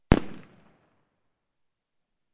爆炸 " 030 烟花
描述：日期：〜12.2015＆amp; ~12.2016Recorder：Olympus LS12Processing：Amplification 详细信息：录制的最响亮的鞭炮和放大器我听过的烟花，有点太近了。被“Paneláks”（google）所包围，创造了非常好的回声。
标签： 大声 动臂 烟花 爆竹 爆炸
声道立体声